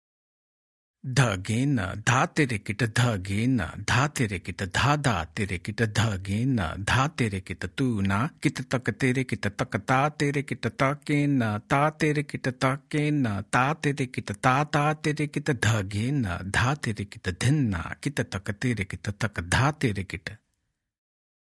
Demonstrations
Spoken